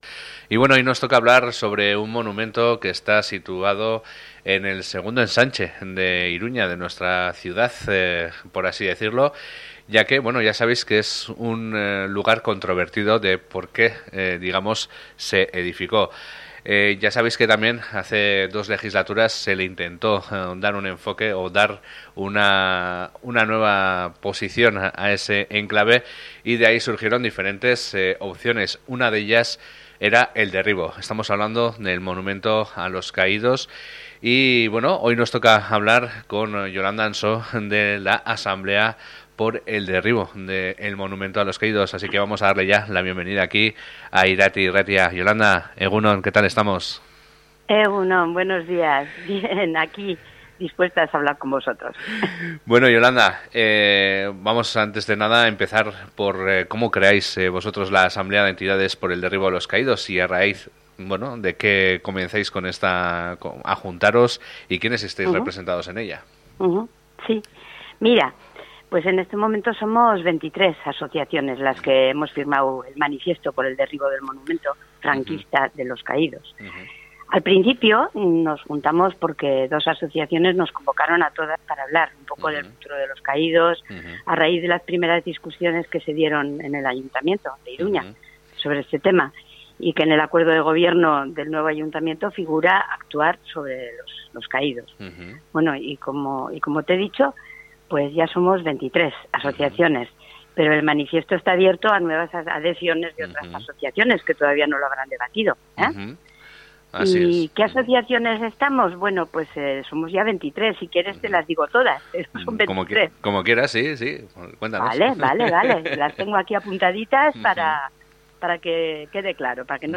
Gaur egu n 23 elkarte memorialista dira barnean eta izaera berdineko elkarte zein norbanakoei deia egin diete eurek ateratako manifestuarekin bat egin dezaten. Guzti honetaz izan gara solasean